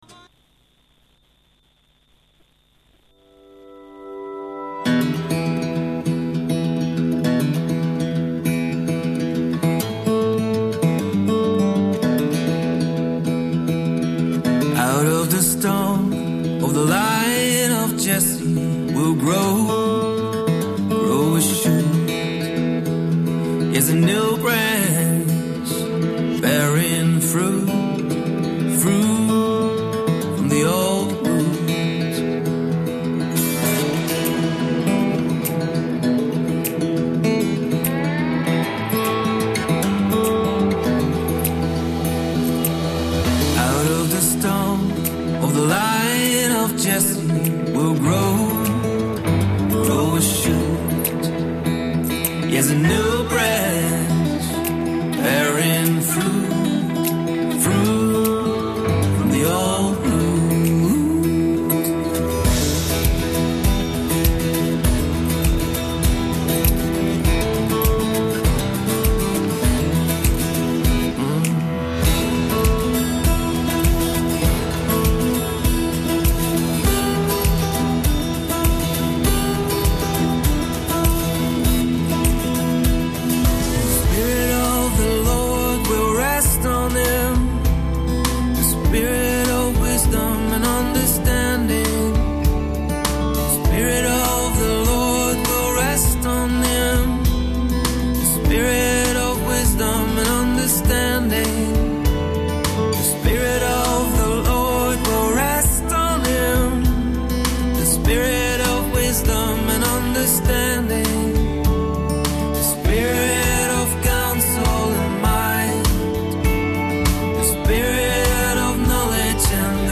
FaithMatters "Its a matter of Faith" Interview and good vibes